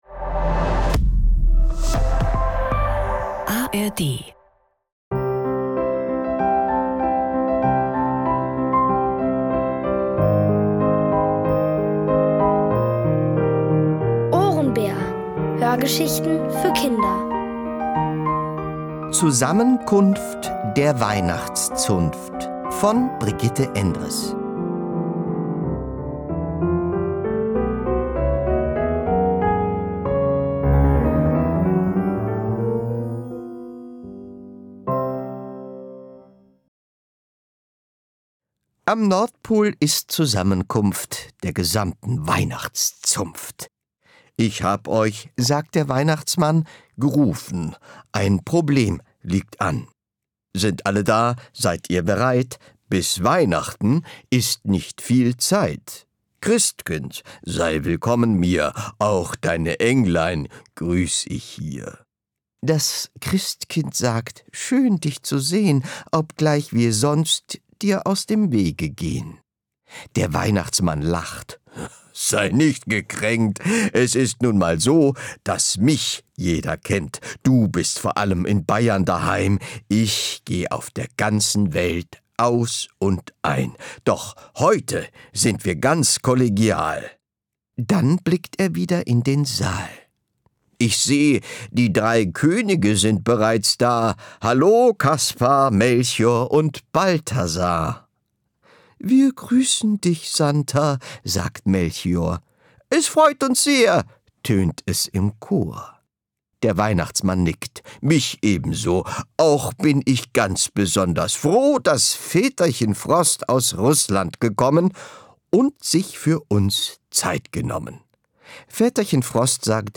Beim OHRENBÄR wird gereimt!
Es liest: Matthias Matschke.